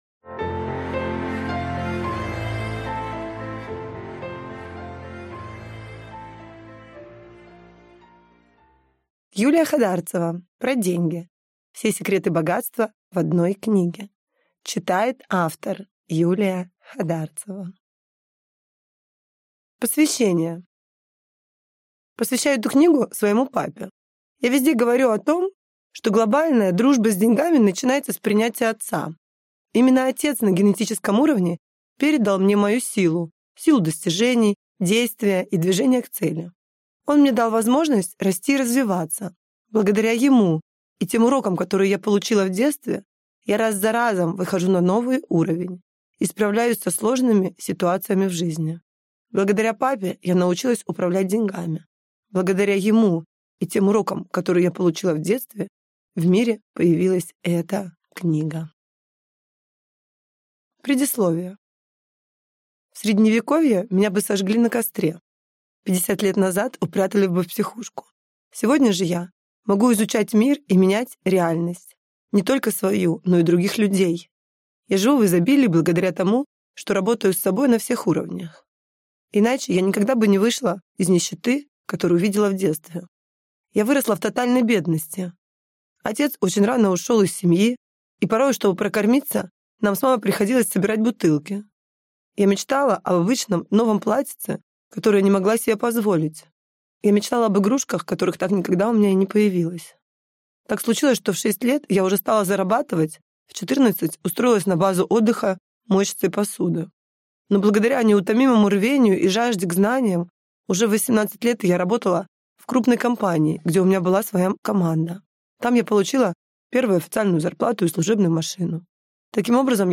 Аудиокнига Про деньги. Все секреты богатства в одной книге | Библиотека аудиокниг